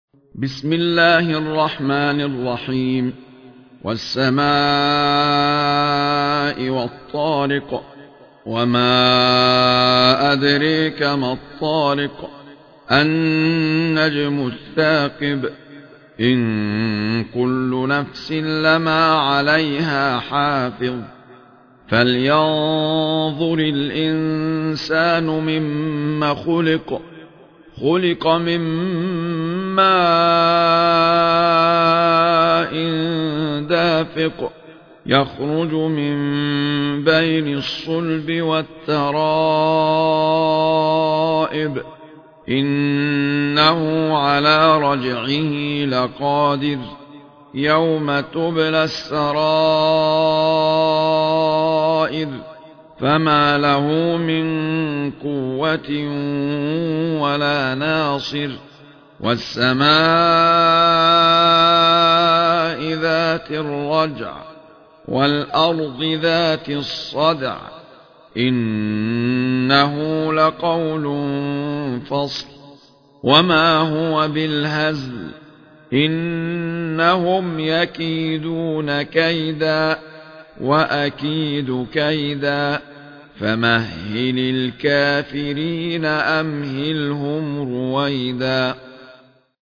المصاحف - أحمد عيسى المعصراوي
المصحف المرتل - ابن وردان عن أبي جعفر